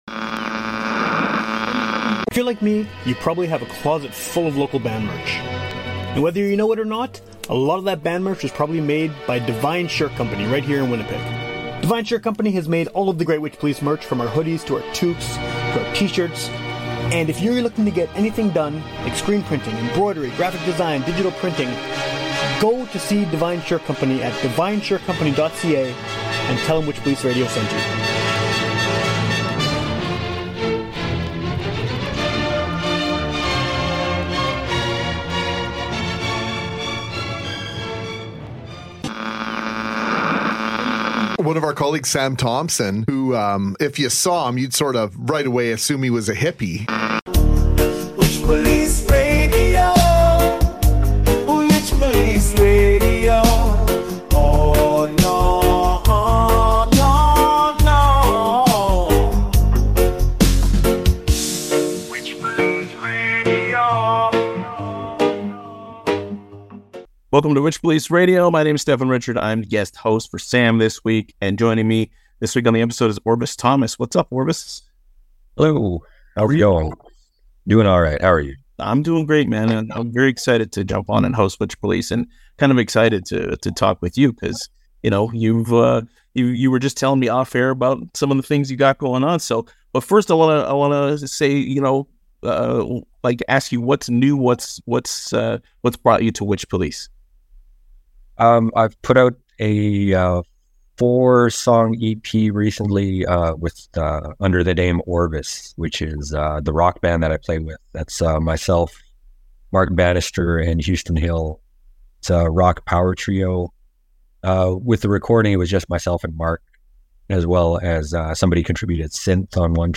Guest hosts, get yer guest hosts here!